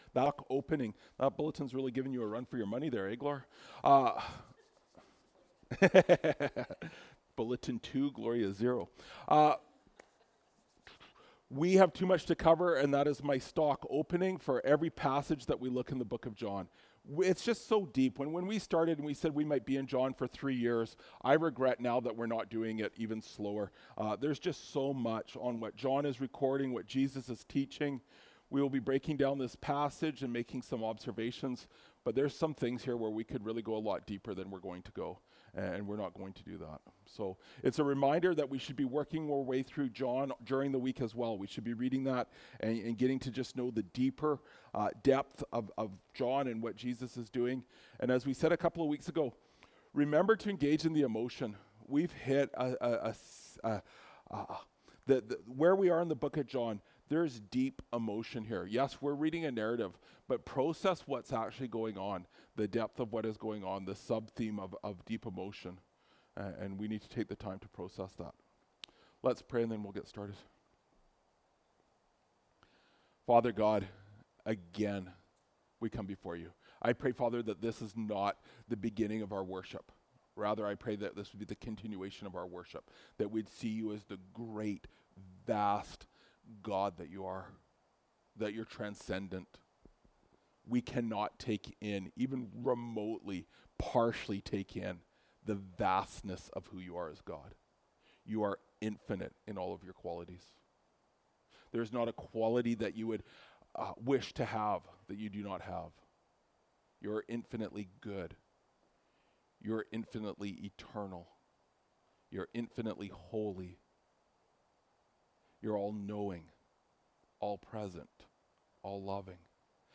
Feb 04, 2024 Questions and Comments (John 13:36-14:14) MP3 SUBSCRIBE on iTunes(Podcast) Notes Discussion Sermons in this Series This sermon was recorded at Grace Church - Salmon Arm and preached in both Salmon Arm and Enderby.